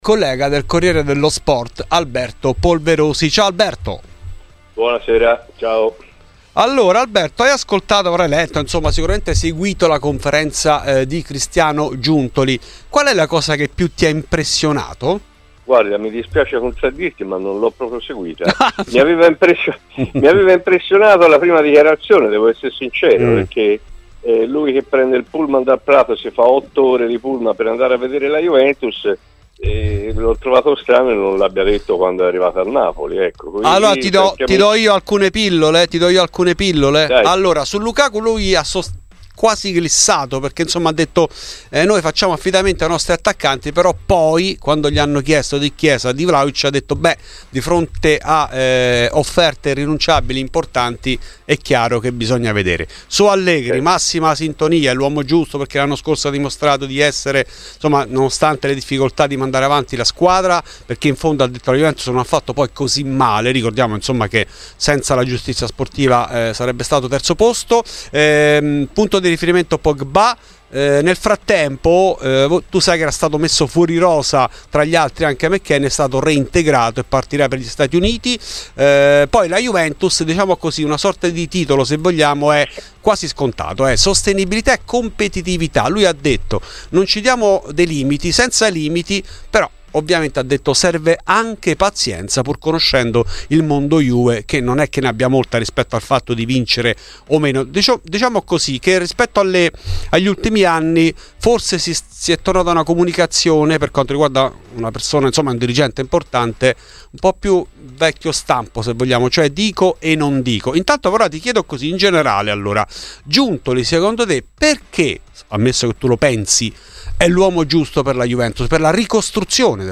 Il nuovo responsabile dell'area sportiva bianconera ha parlato del possibile arrivo di Lukaku e dell'ipotesi addio di Vlahovic e Chiesa , della centralità di Pogba e del suo rapporto con Allegri. In ESCLUSIVA a Fuori di Juve